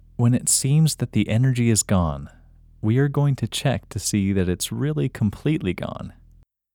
IN – Second Way – English Male 19